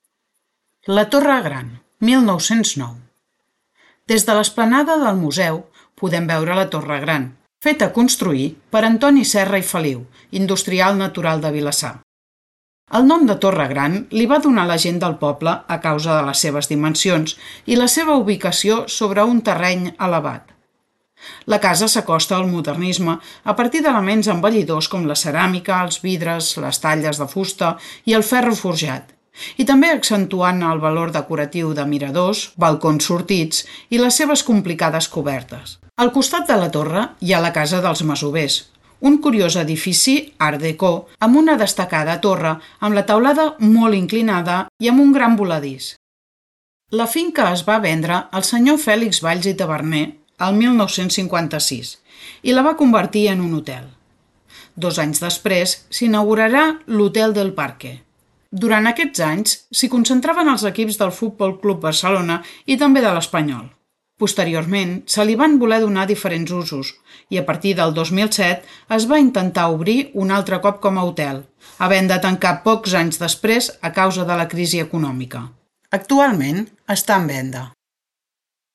• RUTA MODERNISTA AUDIOGUIADA